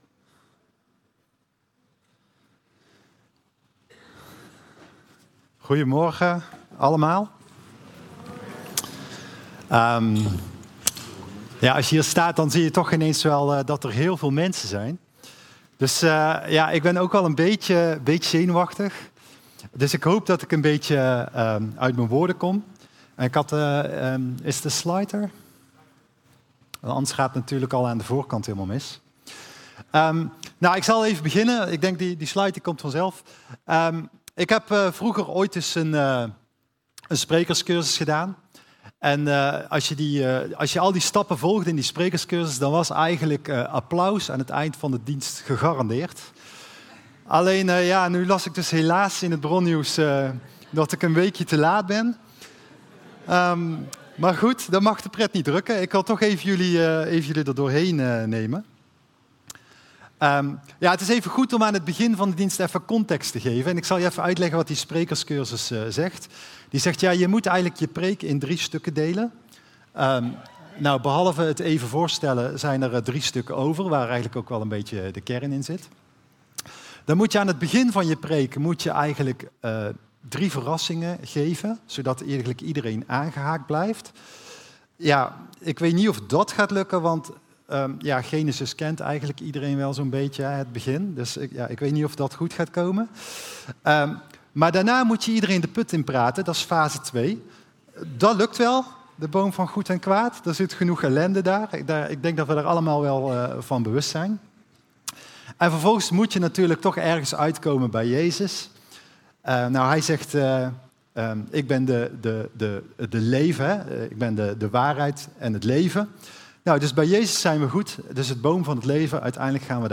Toespraak 22 juni: de boom van kennis van goed en kwaad